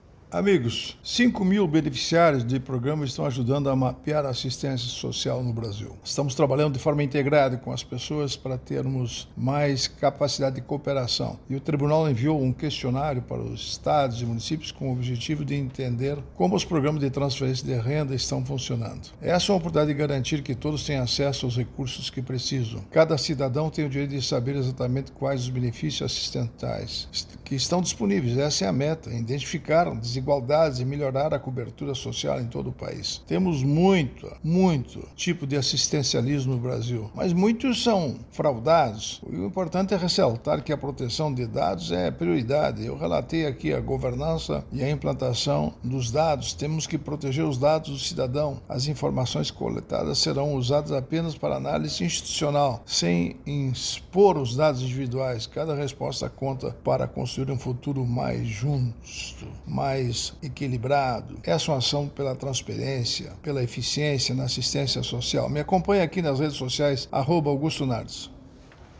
Por Augusto Nardes, ministro do Tribunal de Contas da União.